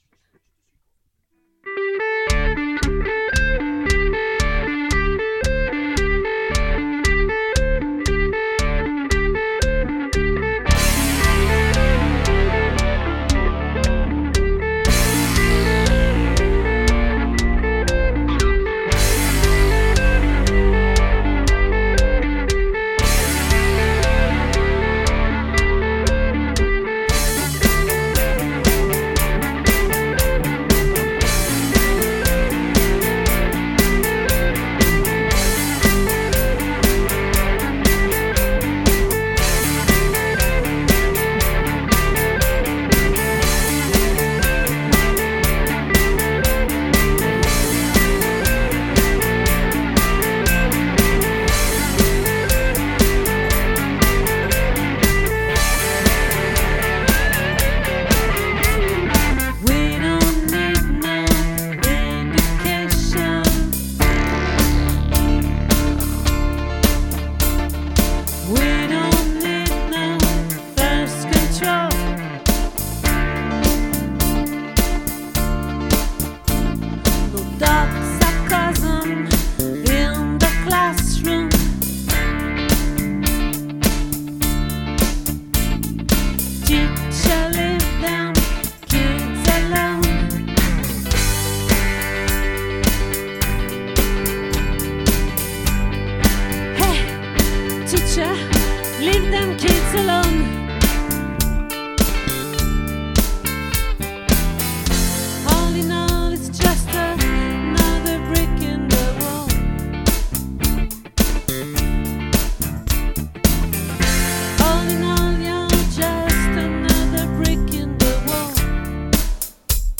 🏠 Accueil Repetitions Records_2024_08_29